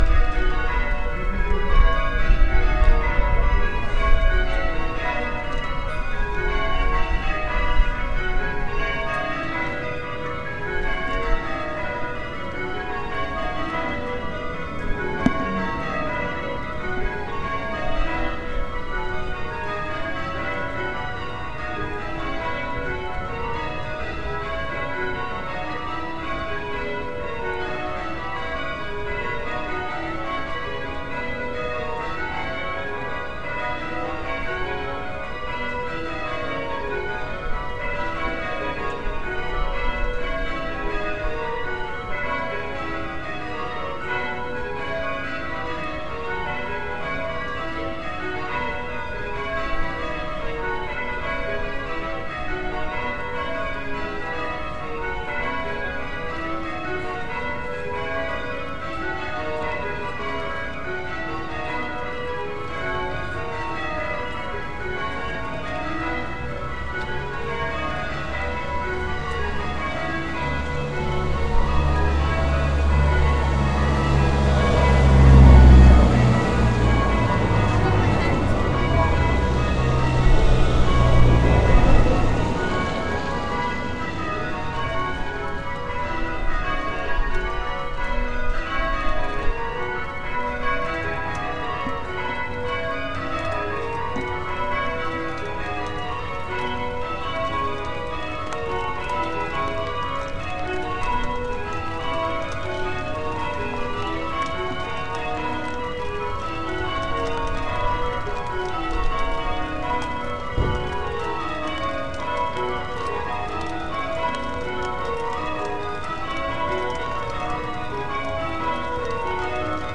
Old St Martin's bells in the rain